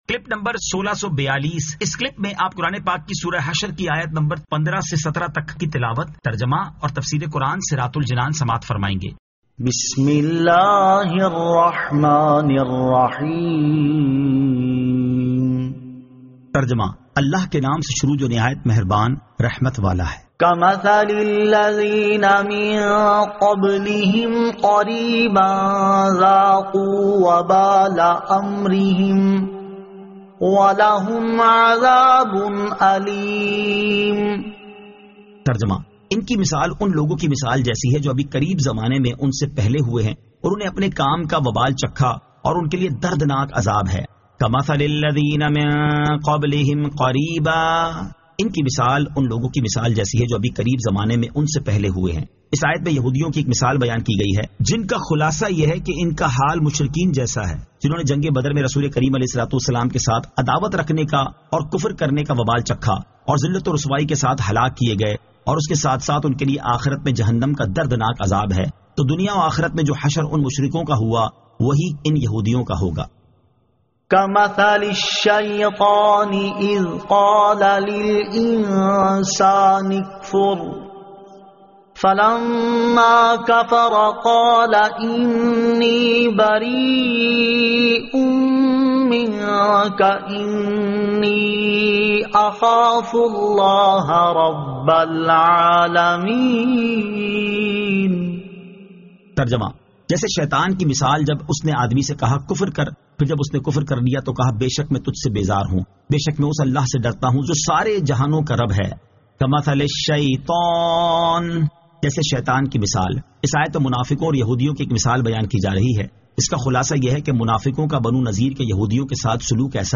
Surah Al-Hashr 15 To 17 Tilawat , Tarjama , Tafseer